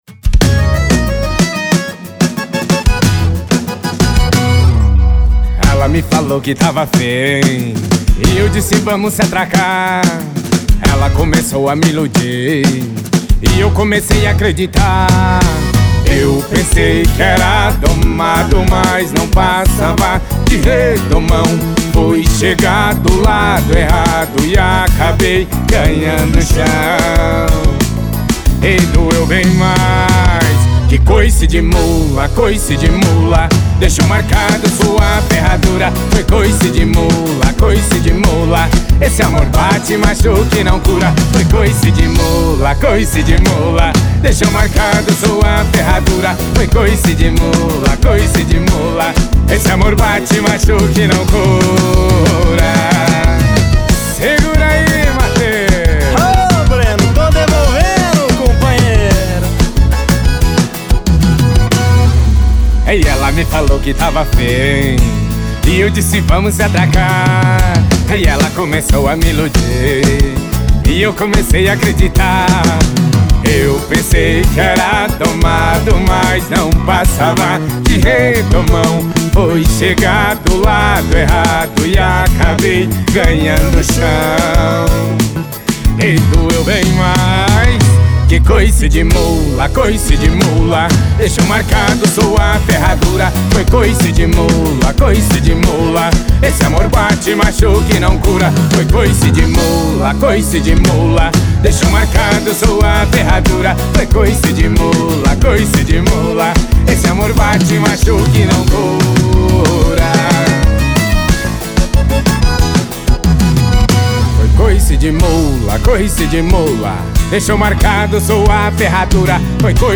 dupla sertaneja
releitura